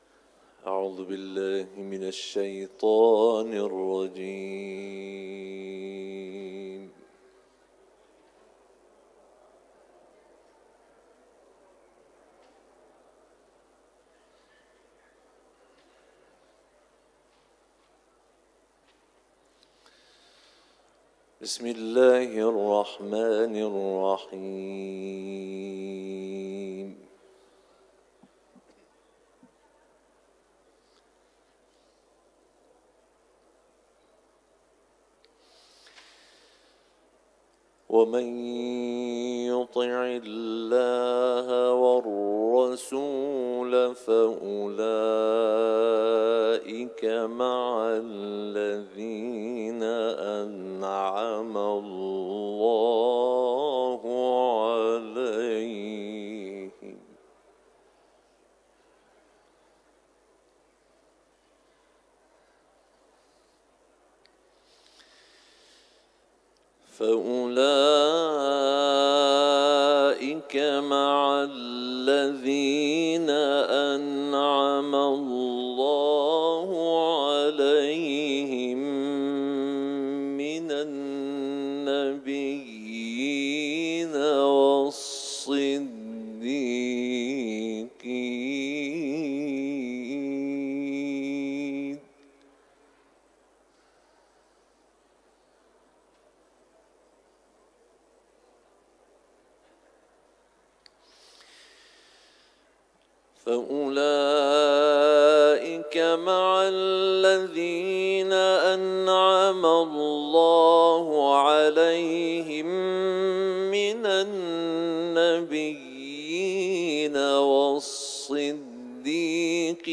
سوره نساء ، تلاوت قرآن